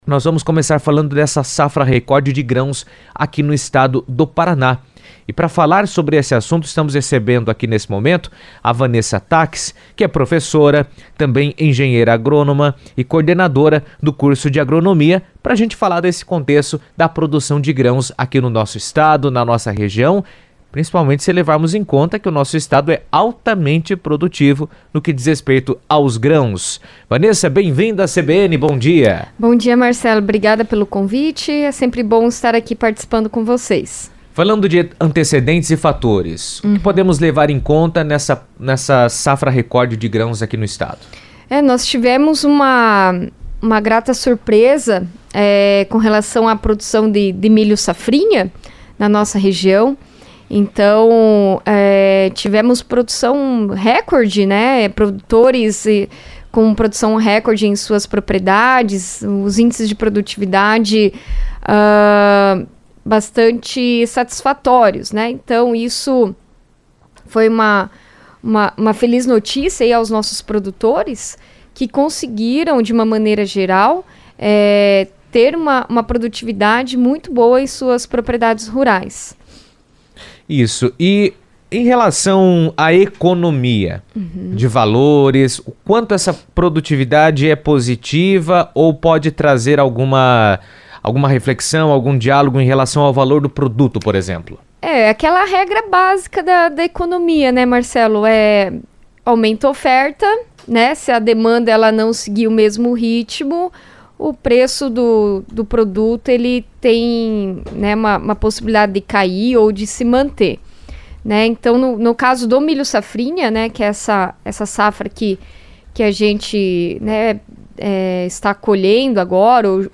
entrevista na CBN